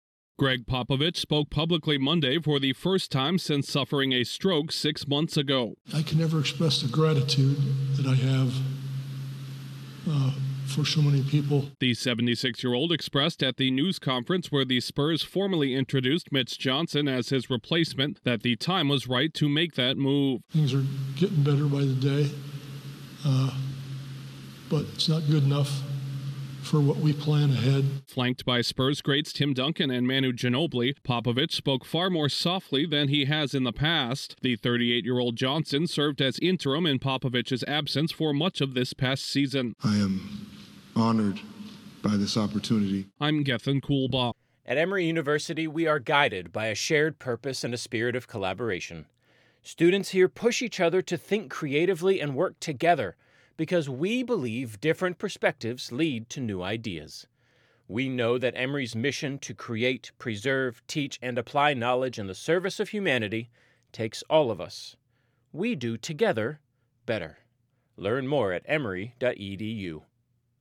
Gregg Popovich speaks at news conference introducing Mitch Johnson as new Spurs coach